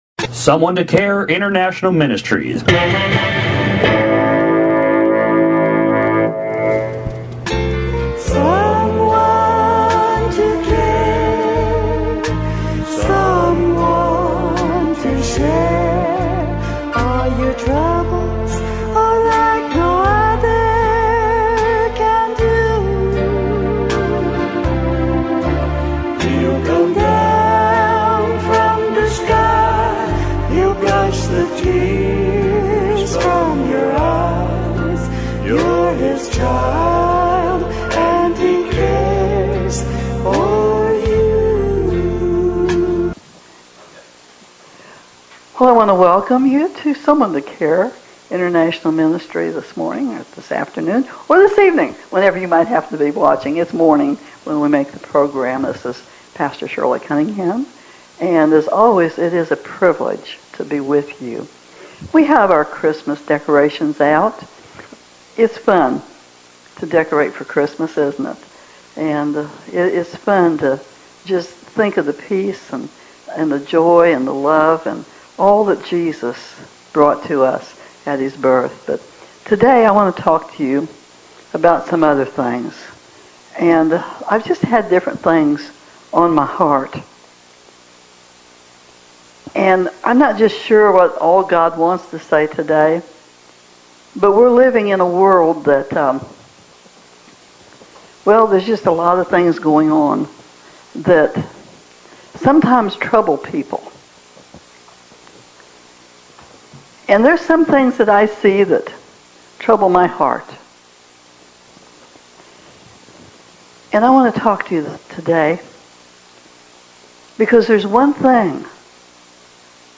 MP 3 Sermons